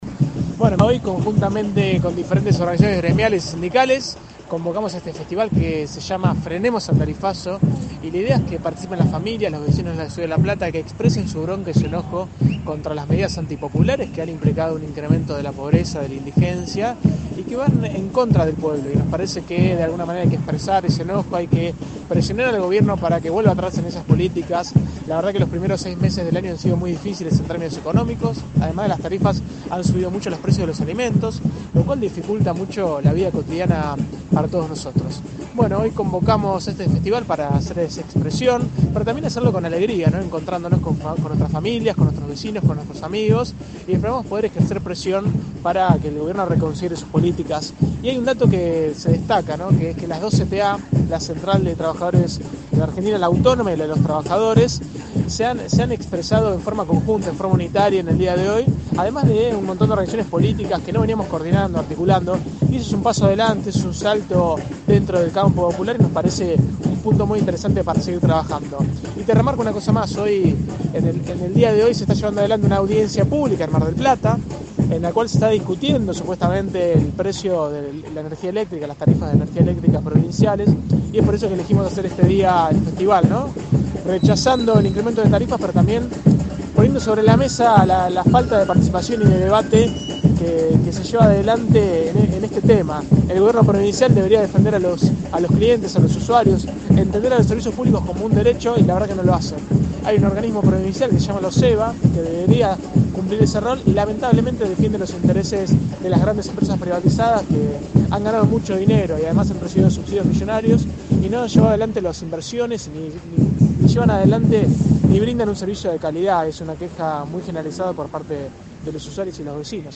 Festival contra el tarifazo en Plaza San Martín